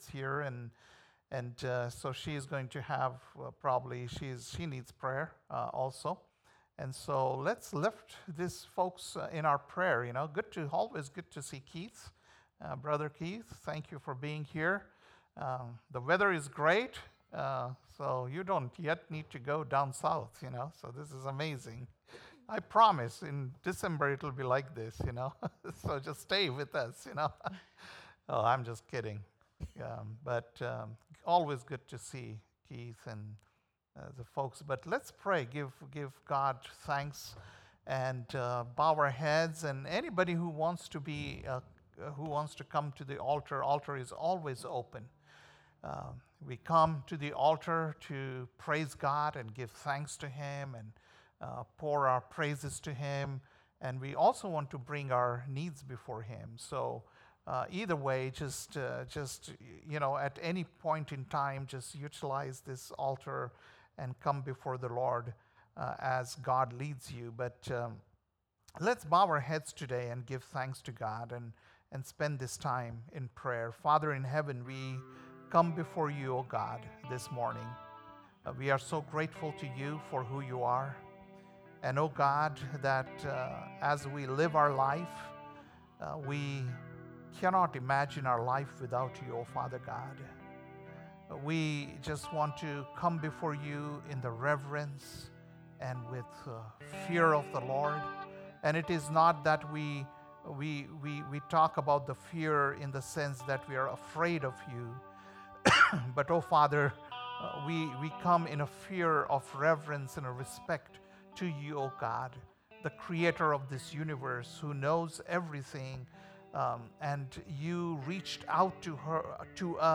September 22nd, 2024 - Sunday Service - Wasilla Lake Church